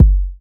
• Solid Urban Bass Drum Single Hit G Key 478.wav
Royality free kick tuned to the G note. Loudest frequency: 93Hz
solid-urban-bass-drum-single-hit-g-key-478-r3g.wav